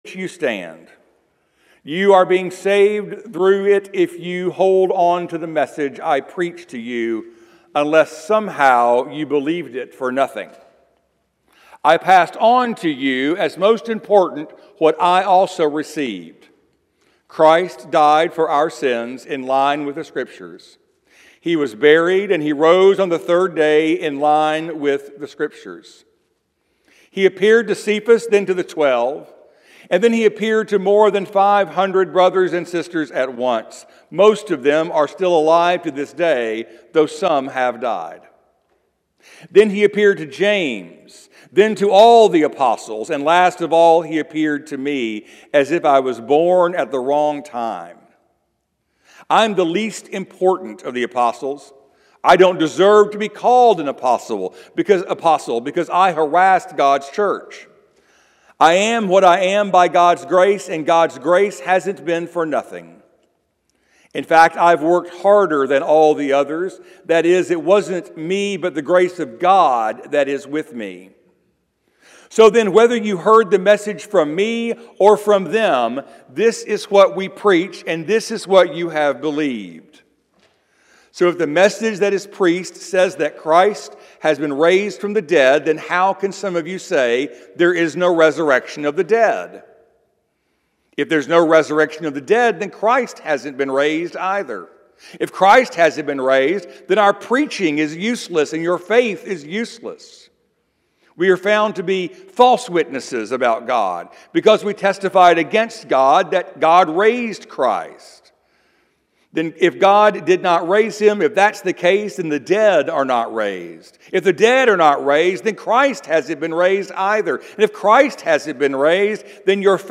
Sermons – Page 3 – Westover Hills Presbyterian Church